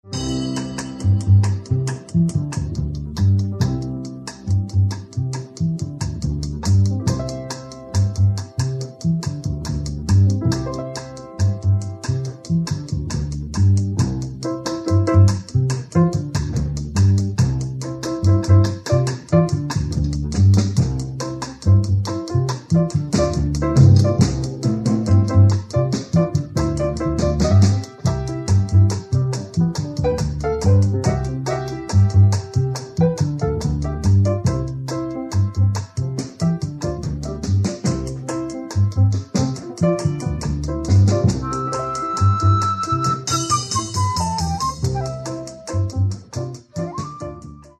Каталог -> Джаз и около -> Ретро